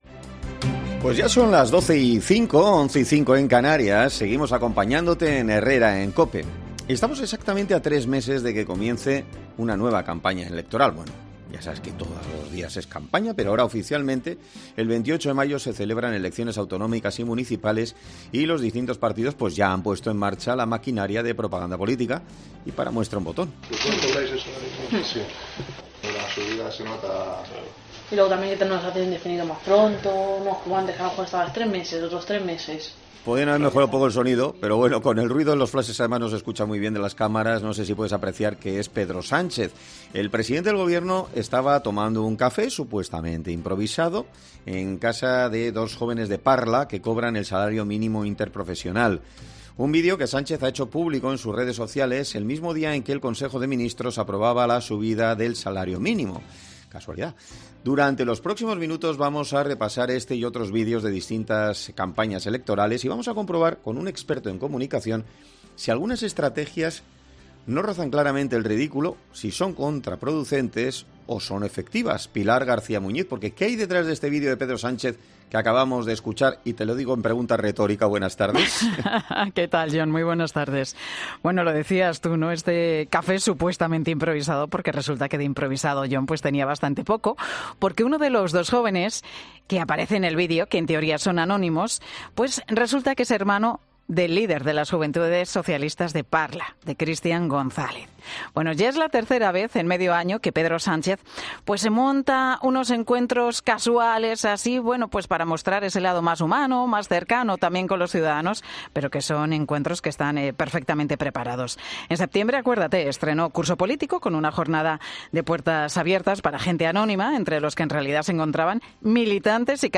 Con el ruido de los flashes de las cámaras no sé si puedes apreciar que es Pedro Sánchez.